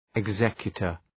{ıg’zekjətər}